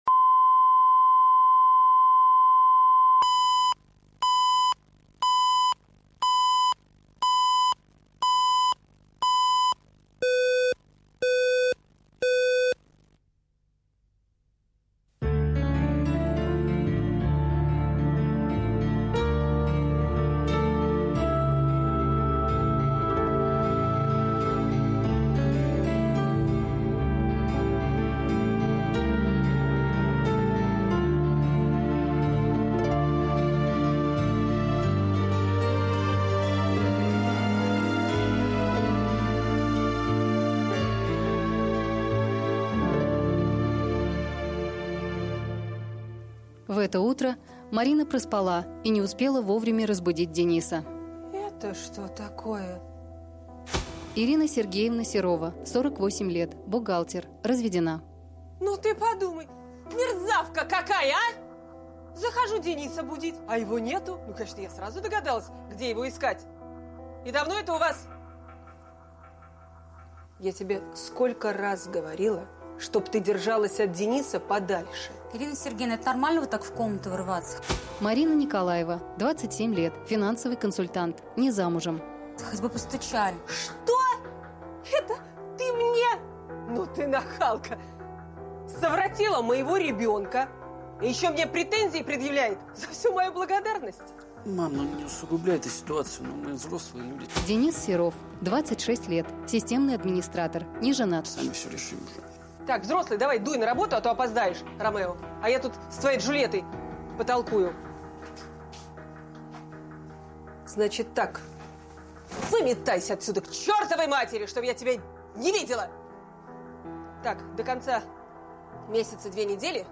Аудиокнига Свадебный генерал